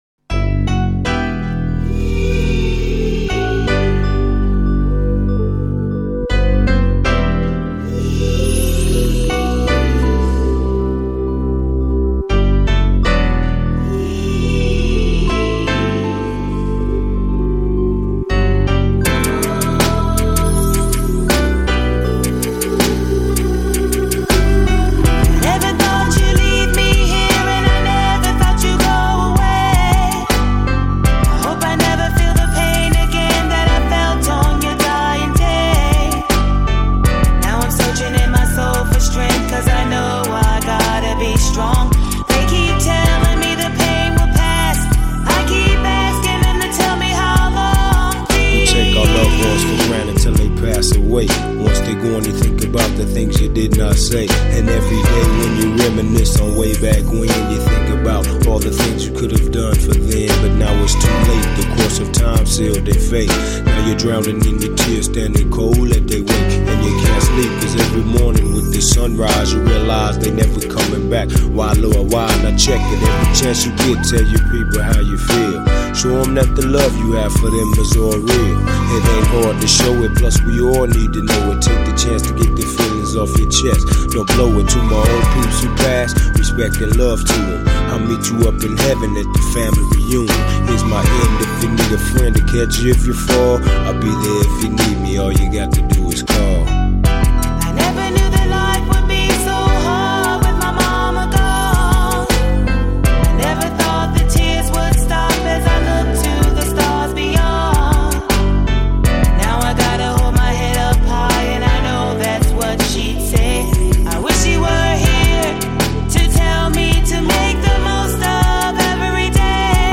Жанр: Rap, Hip Hop